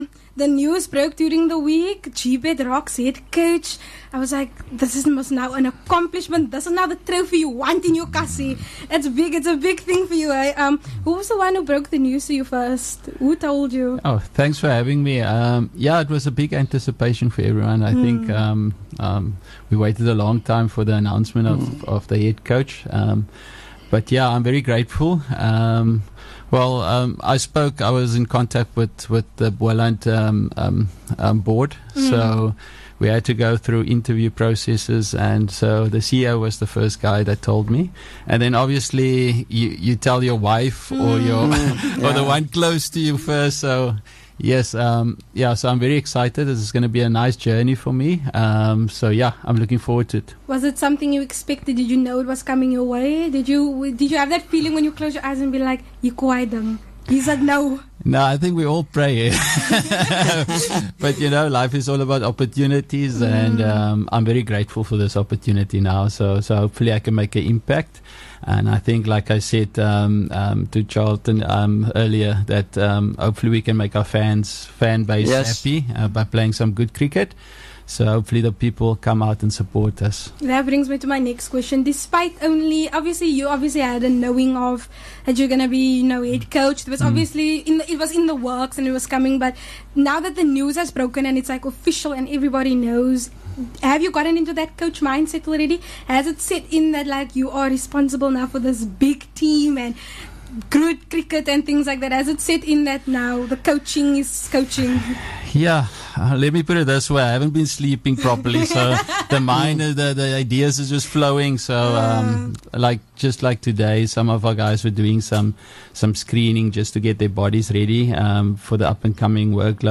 20 Jun Interview with Justin Ontong - Head Coach of GBetRocks